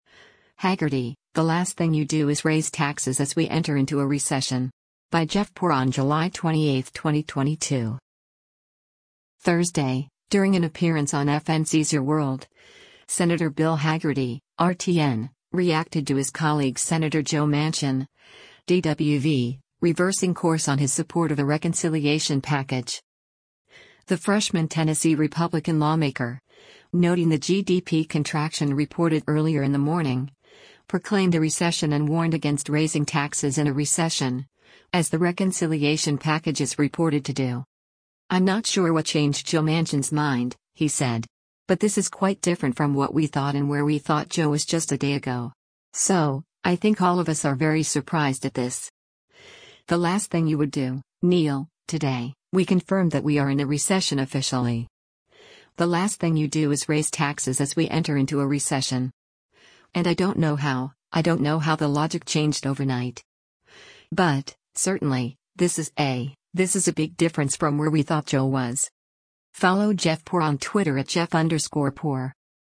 Thursday, during an appearance on FNC’s “Your World,” Sen. Bill Hagerty (R-TN) reacted to his colleague Sen. Joe Manchin (D-WV) reversing course on his support of a reconciliation package.